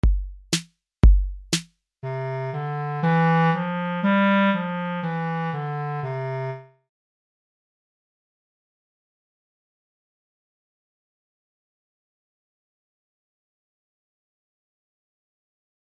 Deseguido atoparedes as diferentes melodías para imitar empregando os vosos instrumentos.